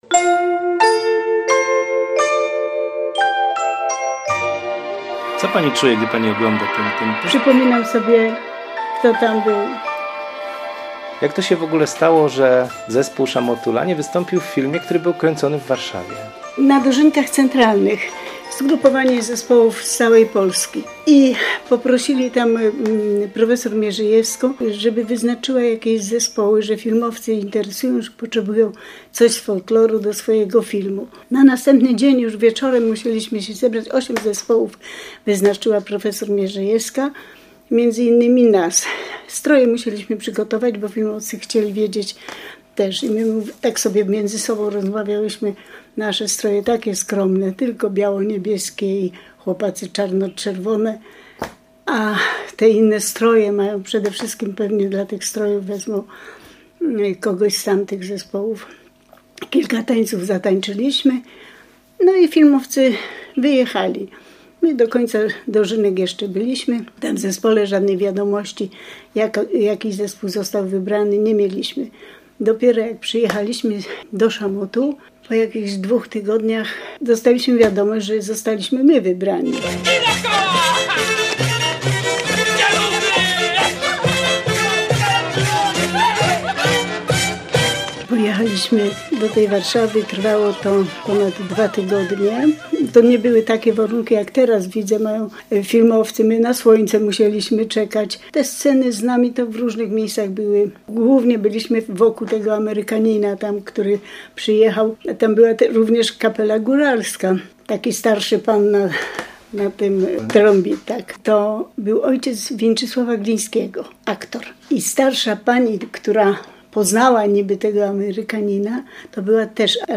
NA ANTENIE: Czas na reportaż